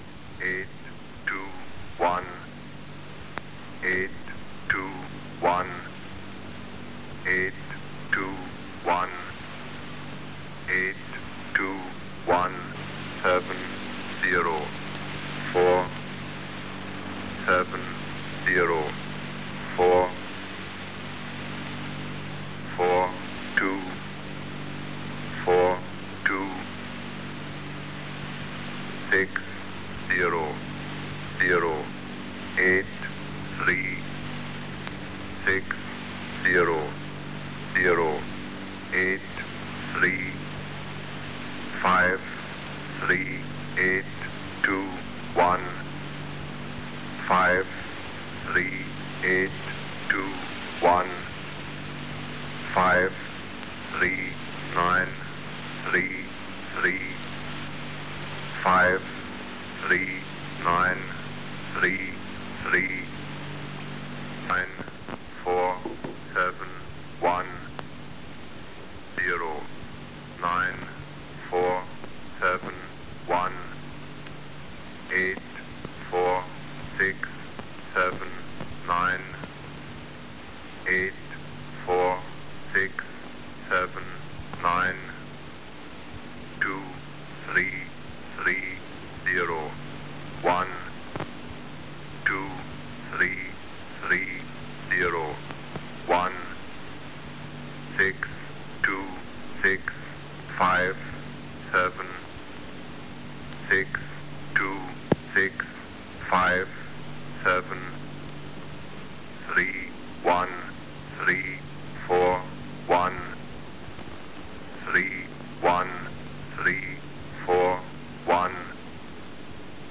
Both male and female voices are used.
Reception quality in North America is usually quite execellent.
Listen to the station The Russian Man in English (ENIGMA E6, also called English Man, unisex voice)